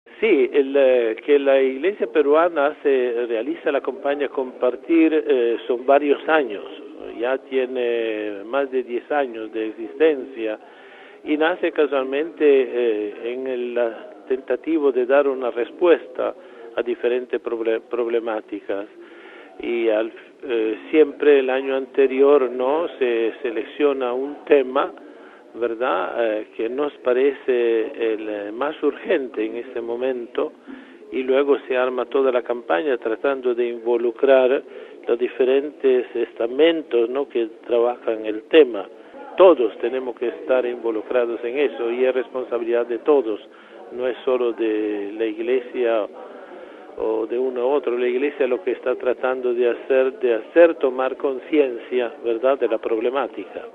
El secretario de la Conferencia Episcopal peruana y presidente de la Campaña Compartir, monseñor Lino Panizza habla a Radio Vaticano sobre esta iniciativa de solidaridad.